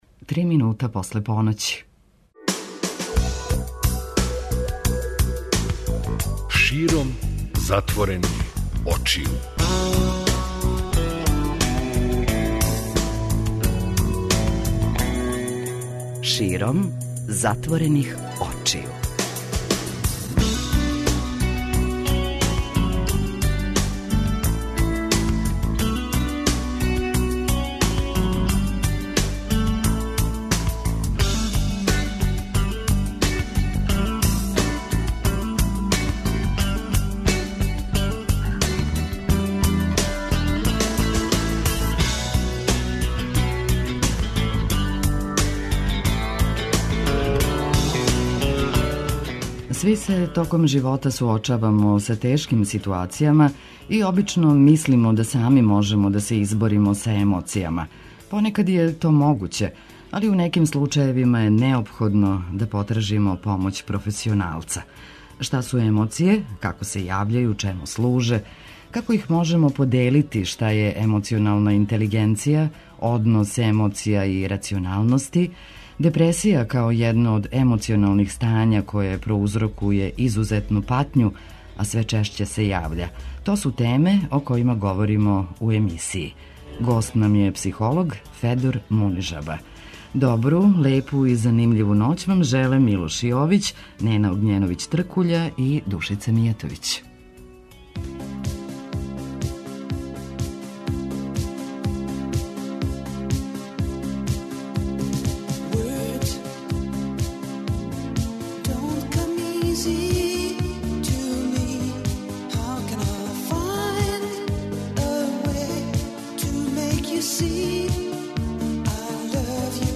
преузми : 54.76 MB Широм затворених очију Autor: Београд 202 Ноћни програм Београда 202 [ детаљније ] Све епизоде серијала Београд 202 Шта рече?!